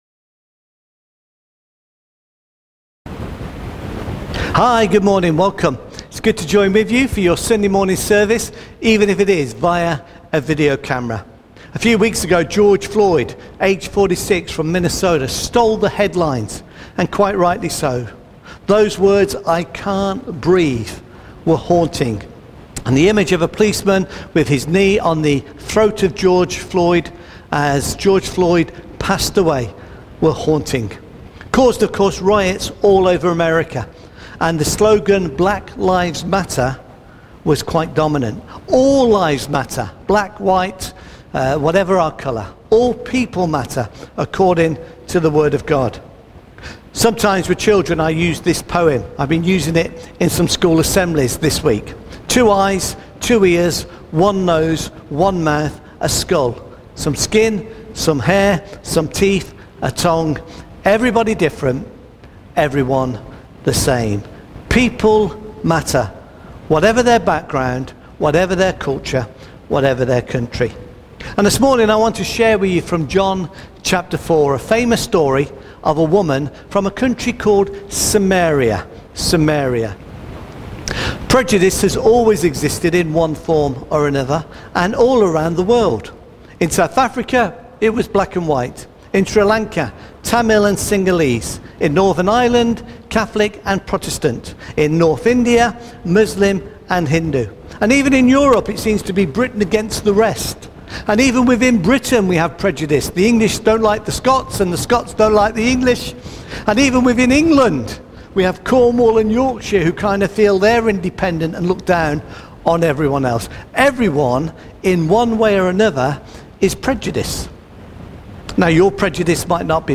Sermon for Charminster Chapel.